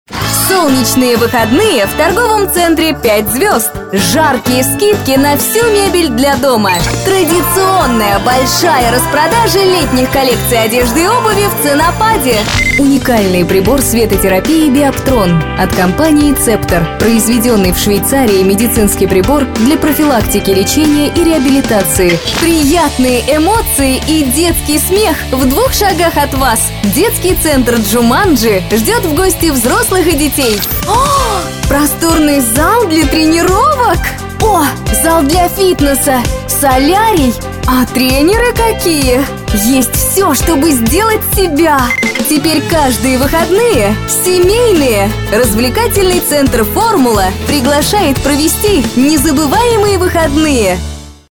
Голос универсальный, подходит как для динамичных и энергичных партий, так и для нежных чувственных начиток! Запись игровых, информационных, имиджевых роликов, IVR, презентаций.
Тракт: : Микрофон Gefell M930 Аудиоинтерфейс Babyface PRO